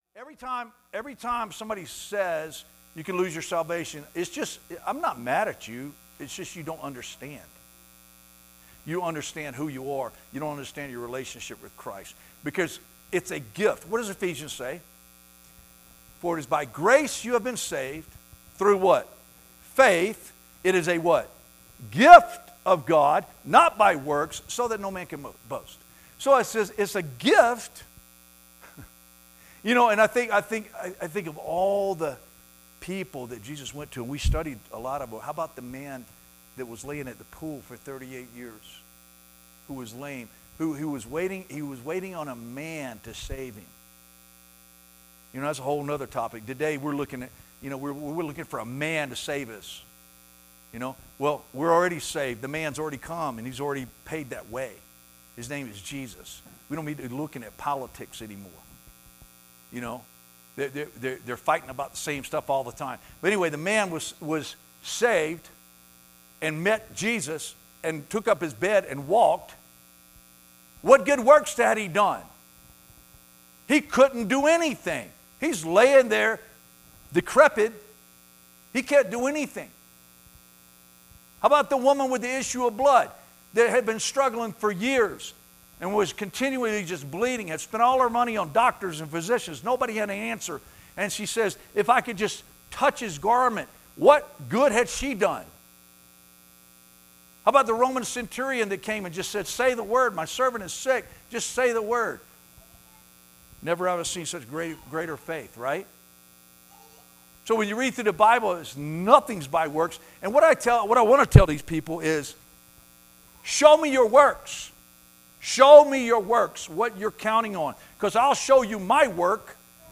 teaches a lesson from the Gospel of John, Chapter 6